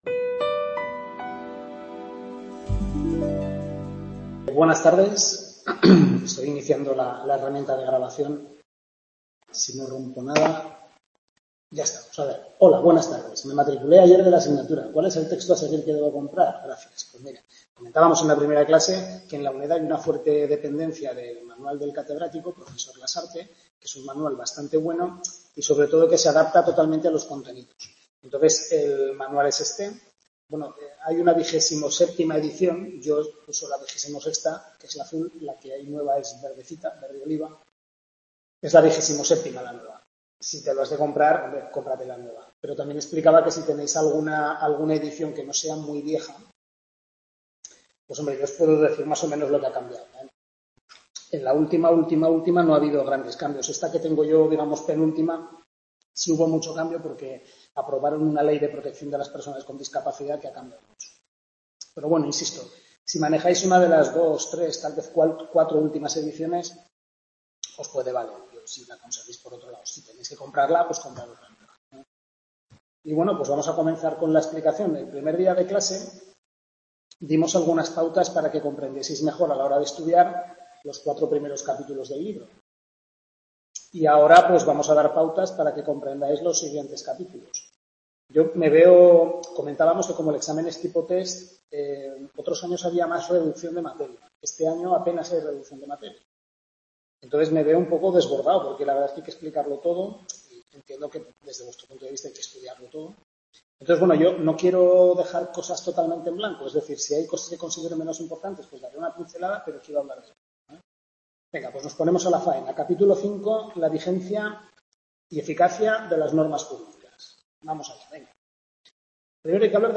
Tutoría 2/6 Civil I, primer cuatrimestre, centro de Calatayud, capítulos 5-9 del Manual del Profesor Lasarte